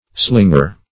Slinger \Sling"er\, n.